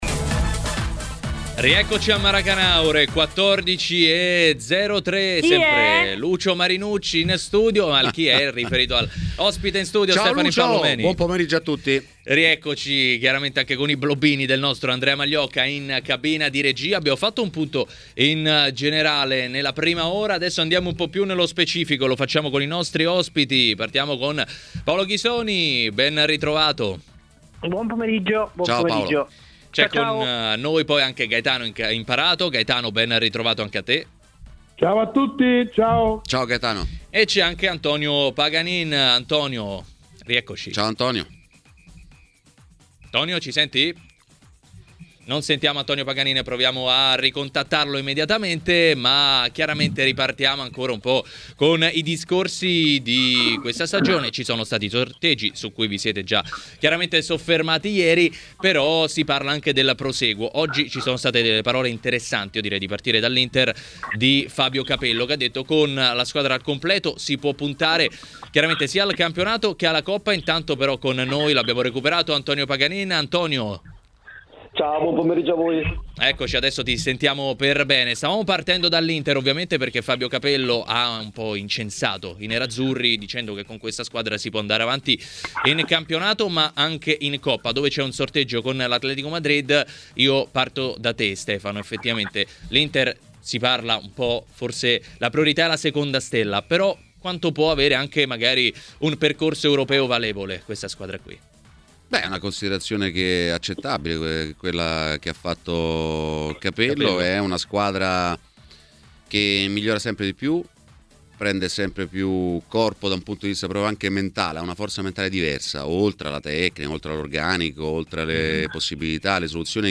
Ai microfoni di TMW Radio, nel corso di Maracanà, l'ex calciatore e ora opinionista Stefano Impallomeni ha parlato di alcuni temi riguardanti la Roma: